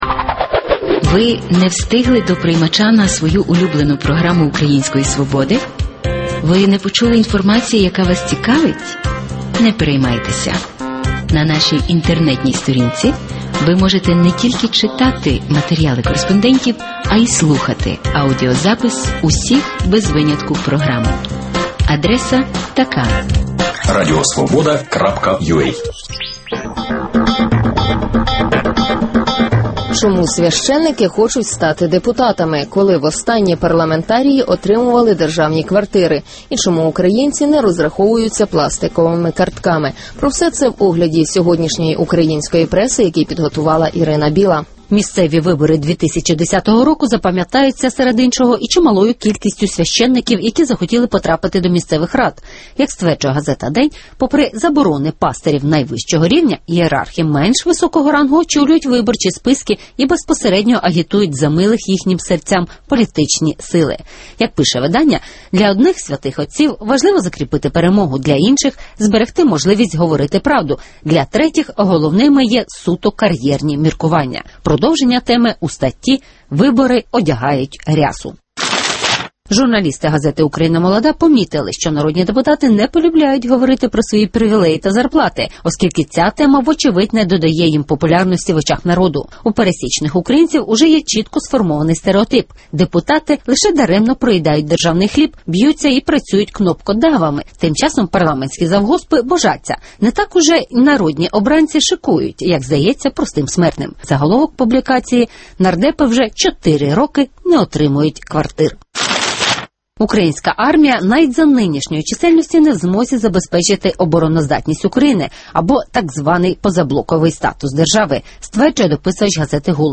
Чому священнослужителі хочуть стати депутатами? (Огляд преси)